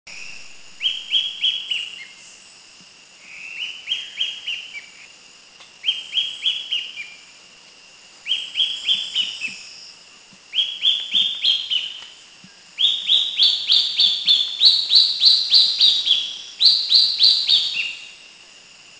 Philippine Hawk-Cuckoo
Cuculus pectoralis
PhilippineHawkCuckooBohol.mp3